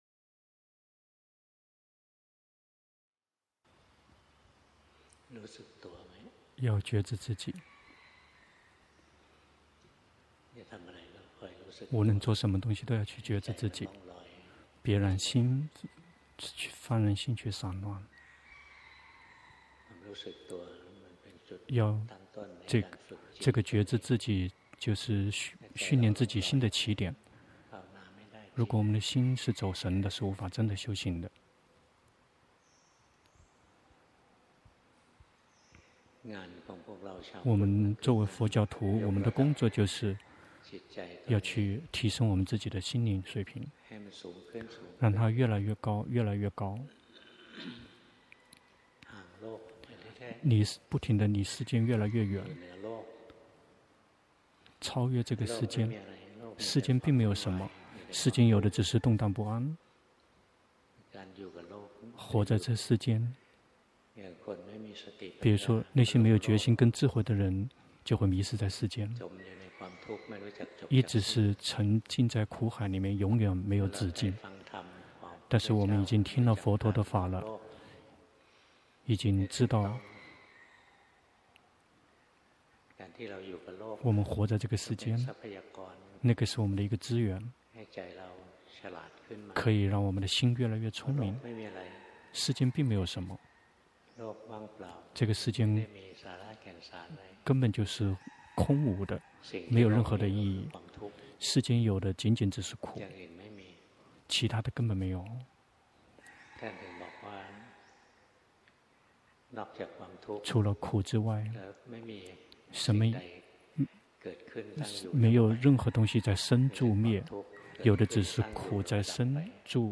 2020年12月27日｜泰國解脫園寺 同聲翻譯